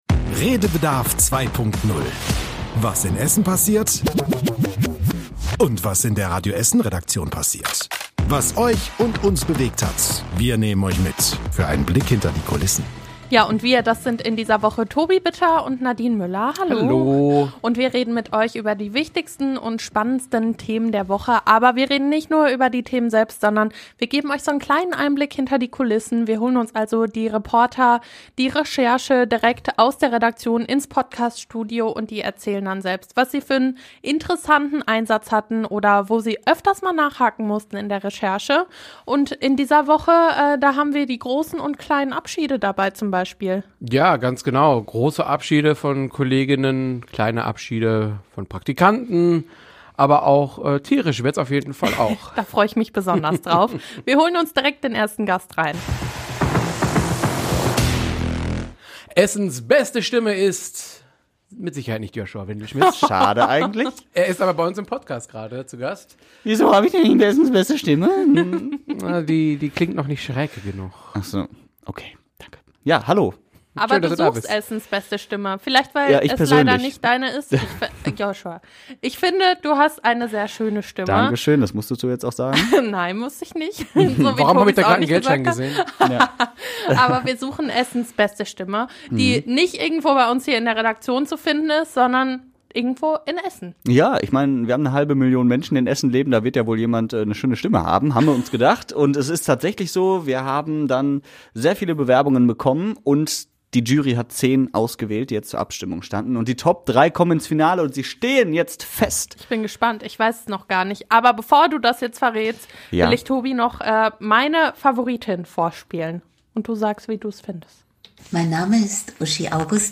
Dafür gibt es neue Stimmen in unserer Nachrichtenredaktion und flatternde Blätter.